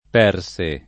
Perse
[ p $ r S e ]